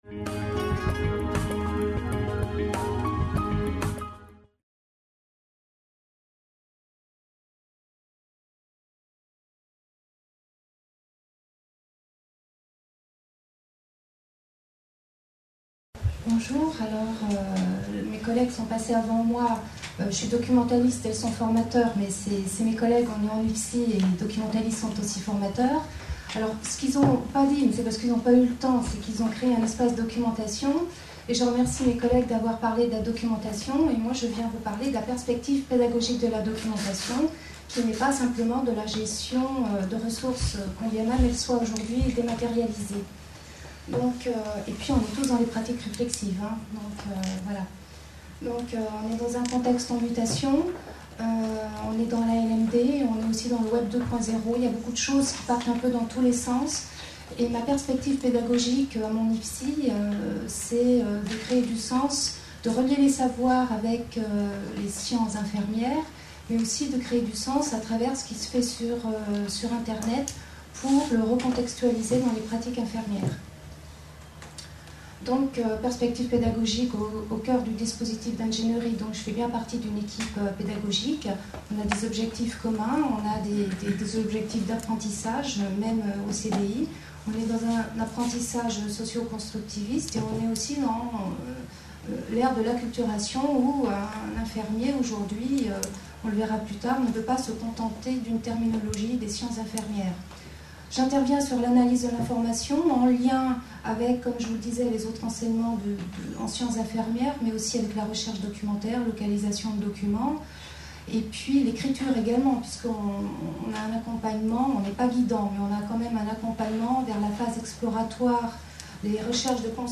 Analyser une information en ligne : une double identité, construire ensemble des critères, l’interactivité.•La recherche documentaire: L’écriture dans tout ça?•Remobiliser des savoirs dans la pratique soignante•Éléments bibliographiques. Conférence enregistrée lors du congrès international FORMATIC PARIS 2011.